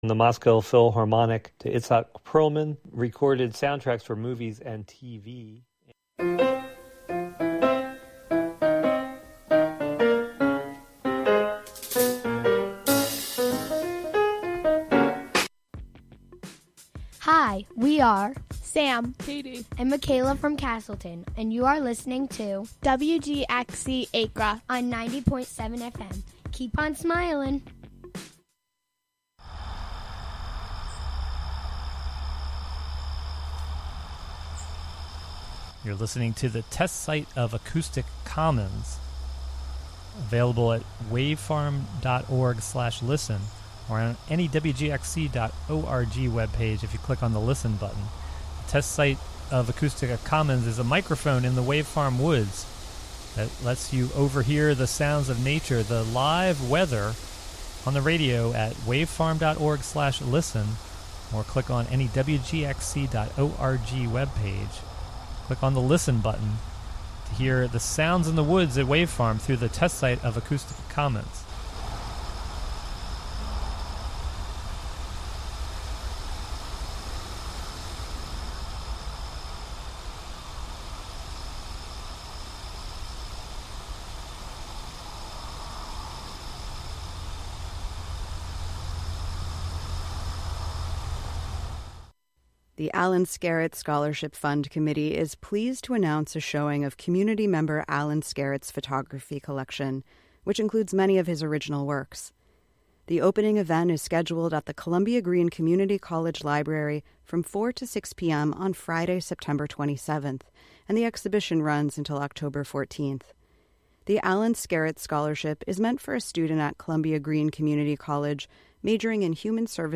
An upbeat music show featuring the American songbook
in a diverse range of genres